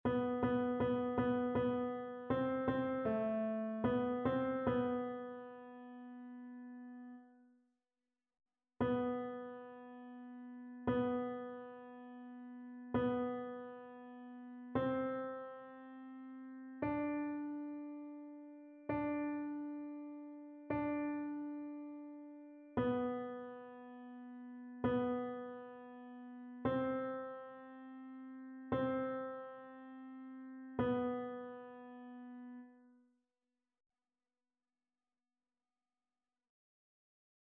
Ténor
annee-a-temps-pascal-3e-dimanche-psaume-15-tenor.mp3